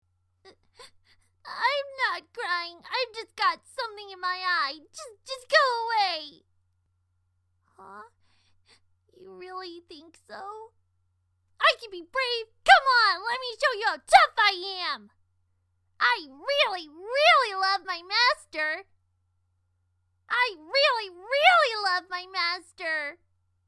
Here are some examples of the voices that original Taffy has had for inspiration.
Taffy_audition.mp3